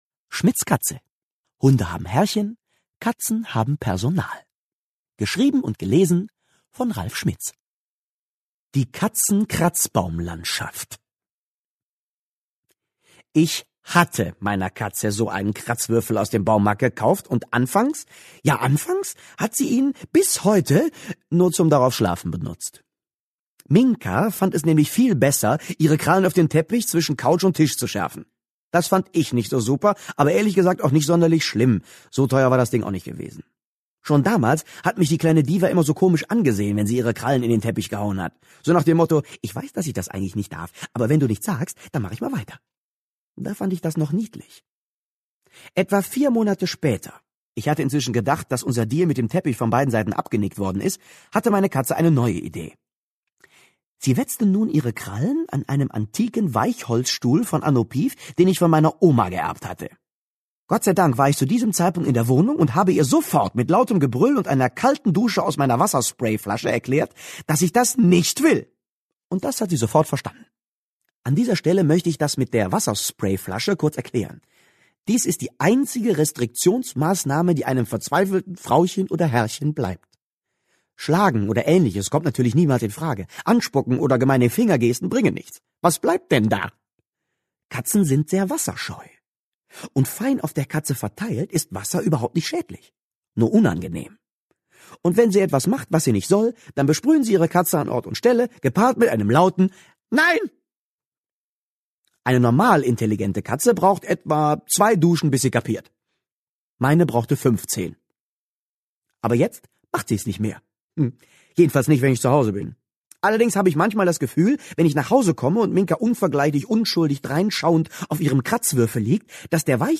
Schmitz' Katze (DAISY Edition) Hunde haben Herrchen, Katzen haben Personal Ralf Schmitz (Autor) Ralf Schmitz (Sprecher) Audio-CD 2009 | 1.